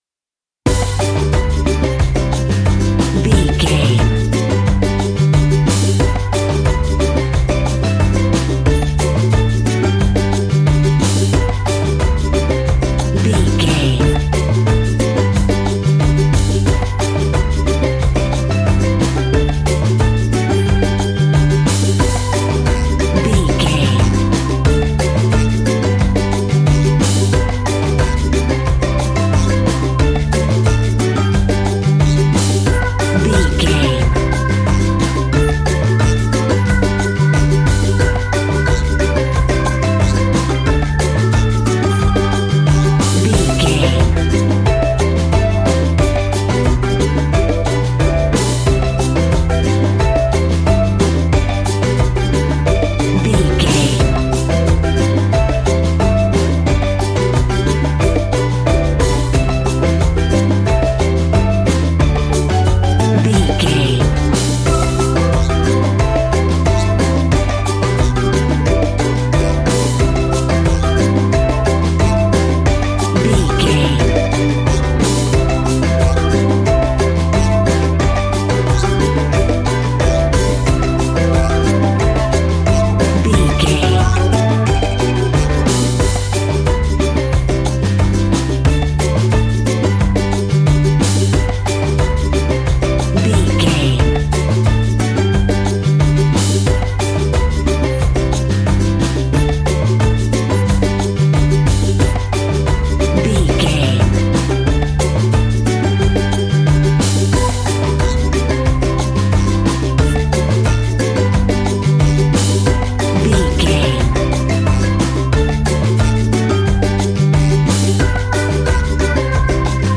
A groovy and upbeat piece of island summer sunshine music.
That perfect carribean calypso sound!
Uplifting
Ionian/Major
steelpan
drums
percussion
bass
brass
guitar